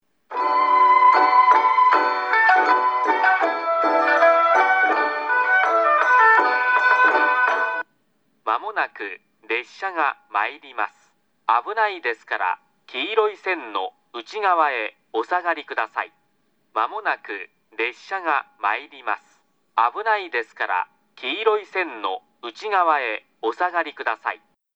上り列車接近放送　男声